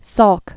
(sôlk), Jonas Edward 1914-1995.